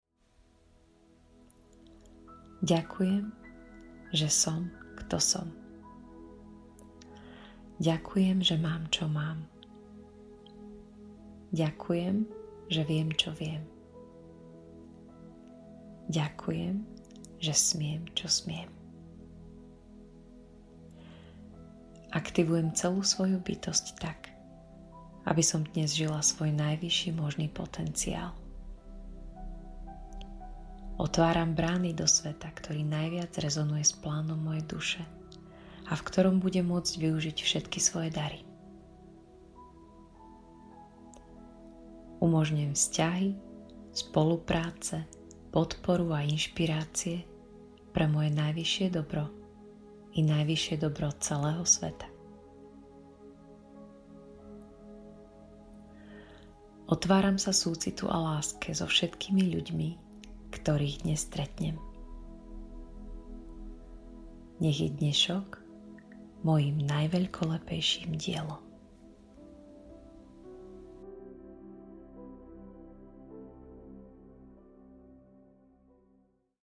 1. Každé ráno ideálne hneď po prebudení sa pohodlne usaďte, zatvorte oči a ponorte sa do vedenej vizualizácie.